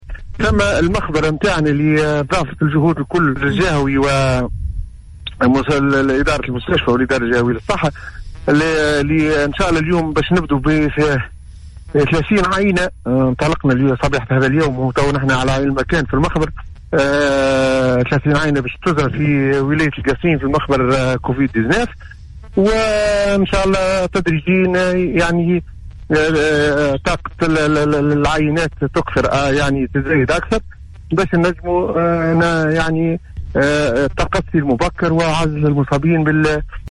اكد المدير الجهوي للصحة  الدكتور عبد الغني الشعباني صباح اليوم في مداخلته ببرنامج نهارك زين  براديو السيليوم اف انه سينطلق  اليوم العمل في المخبر التحليلي الجرثومي لكوفيد 19 بالقصرين عبر اجراء تحليل 30 عينة   و توفير المستتلزمات الضرورية للاسراع من عملية التقصي للفيروس و يسهر على العمل بهذا المخبر فريق عسكري مختص.